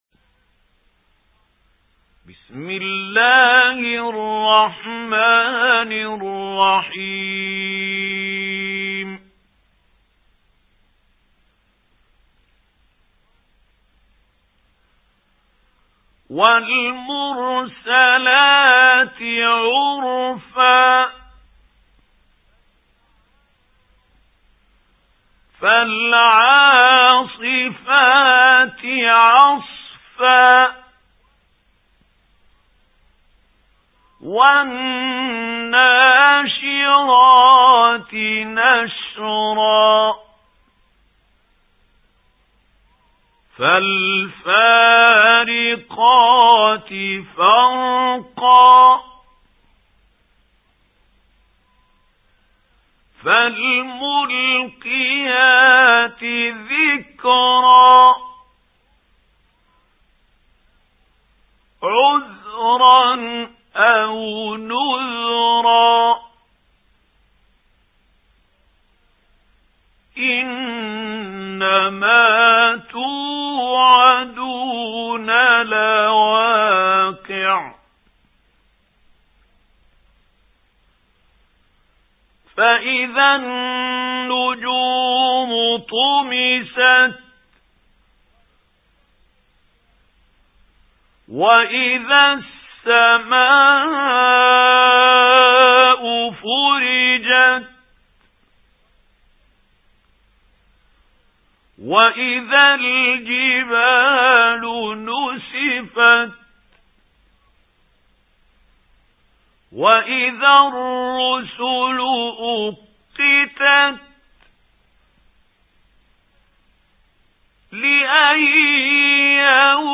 سُورَةُ المُرۡسَلَاتِ بصوت الشيخ محمود خليل الحصري